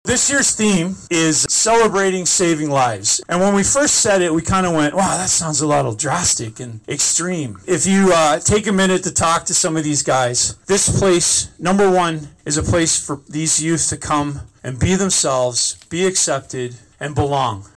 spoke to the crowd before the walk.